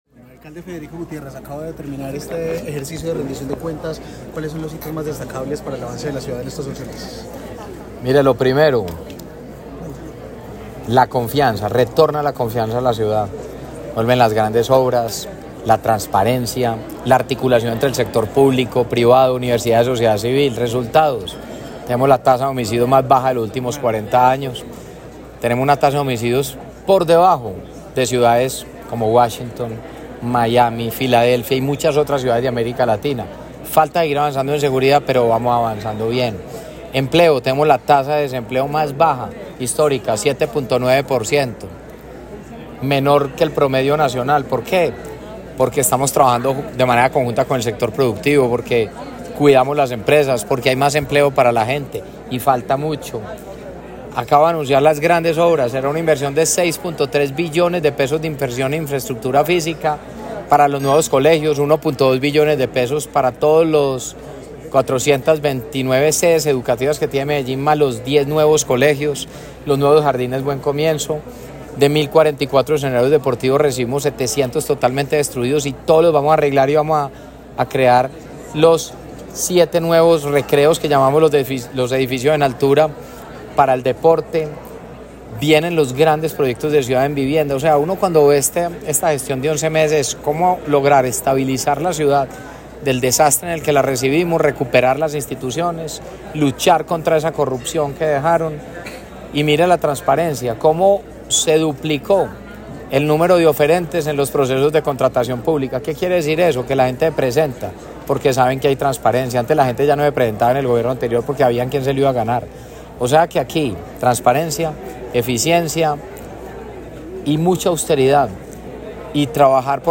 Palabras de Federico Gutiérrez Zuluaga, alcalde de Medellín